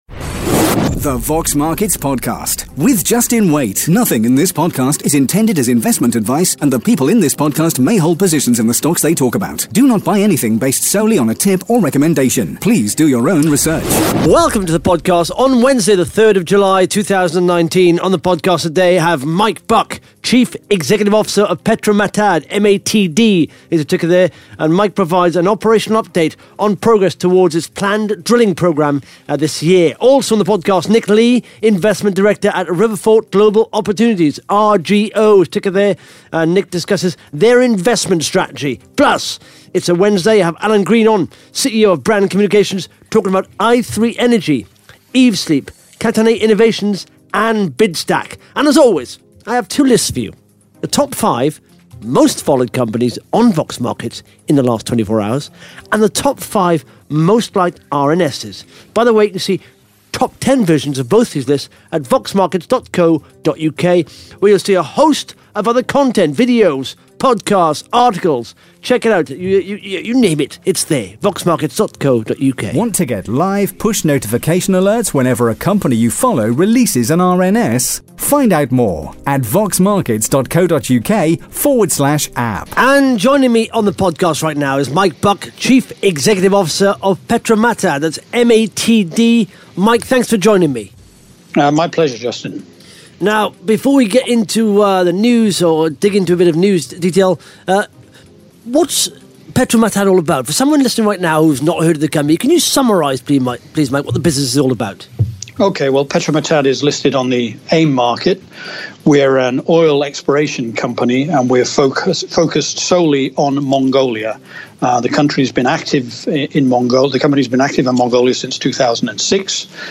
(Interview starts at 11 minutes 42 seconds)